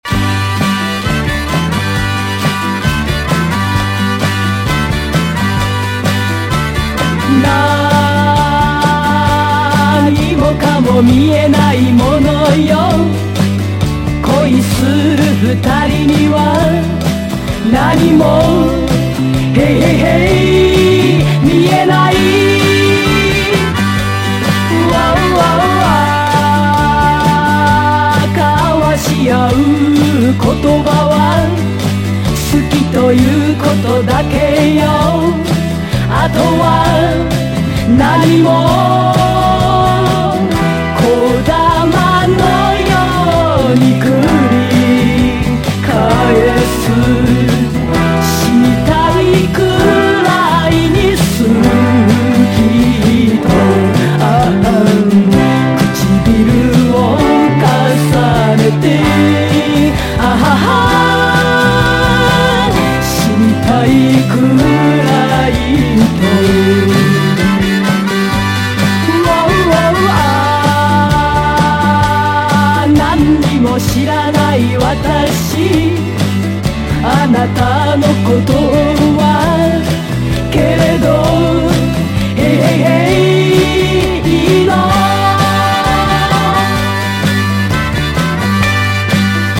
JAPANESE / 60'S/70'S (JPN)
一度聴いたら忘れさせてくれないアシッド・フォークに通ずる世界。